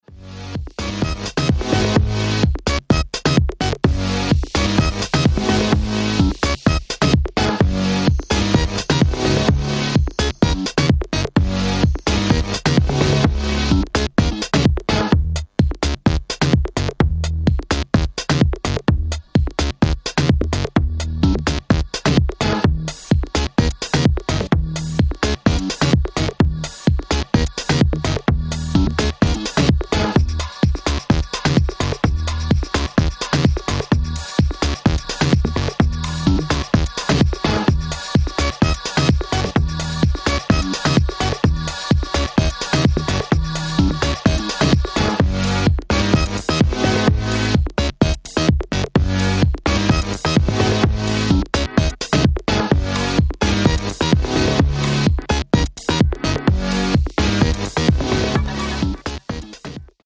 here is their crunchy take on the tech house phenomenom.